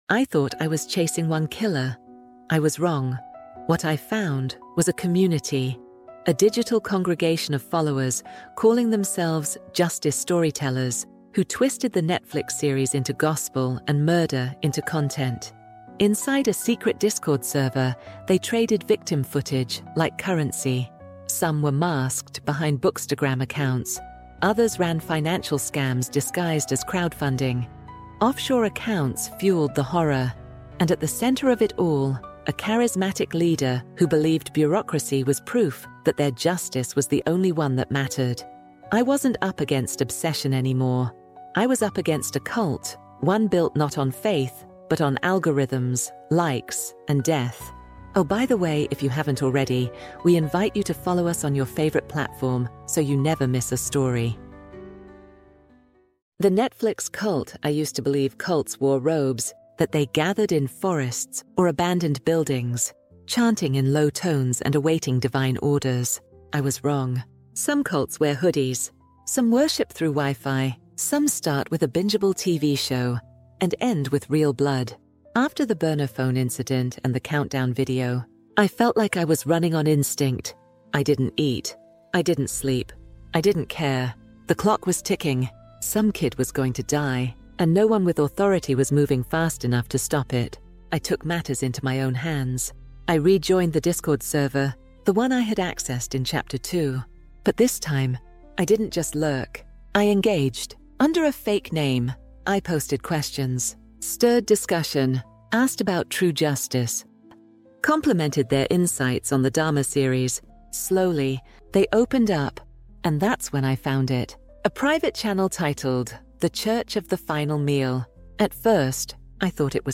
True Crime | Shadows of Justice EP4 | The Netflix Cult | Audiobook